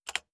keyboard_key.wav